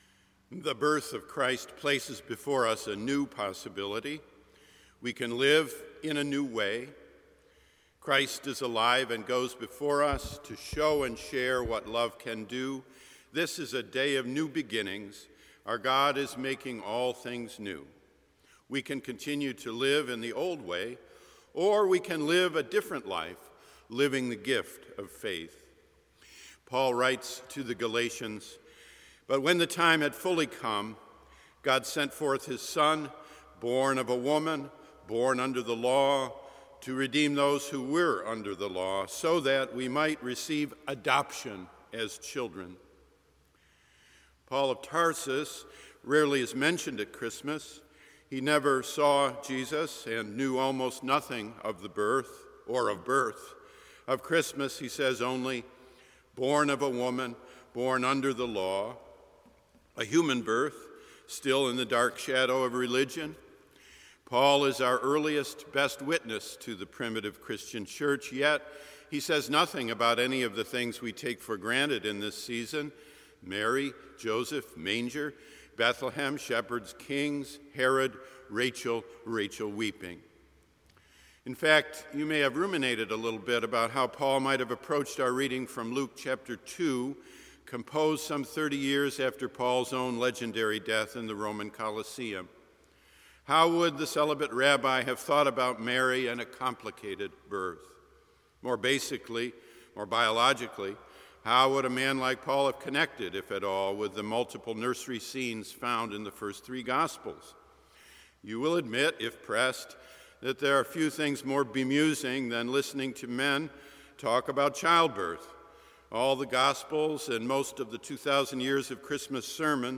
By Marsh Chapel Click here to hear the full service Galatians 4: 4-7 Click here to hear just the sermon Preface The birth of Christ places before us a new possibility.